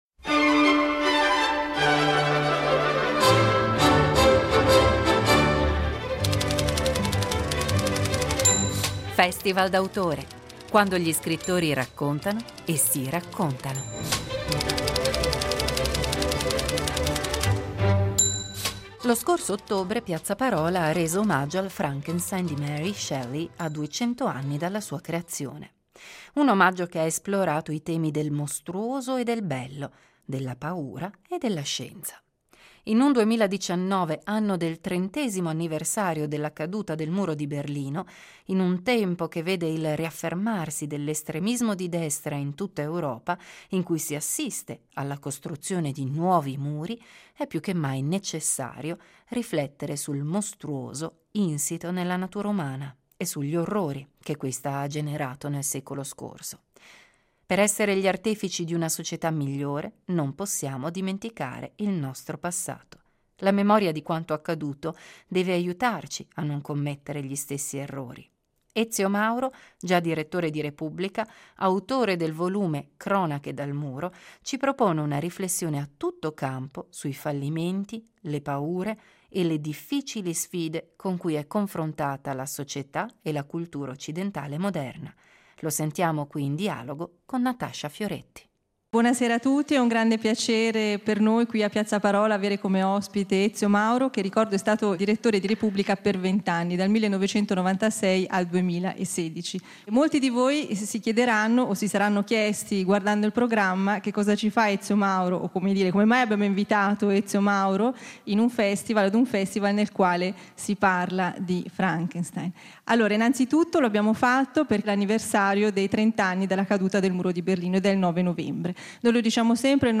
Il meglio dai Festival letterari della regione